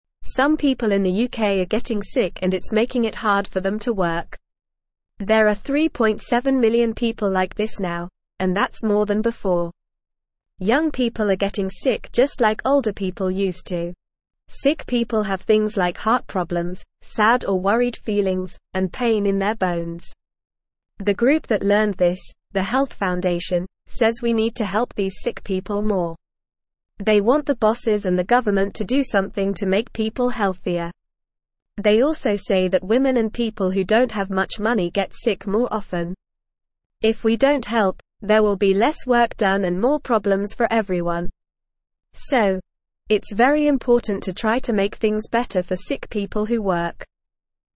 Normal
English-Newsroom-Beginner-NORMAL-Reading-More-People-in-the-UK-Getting-Sick.mp3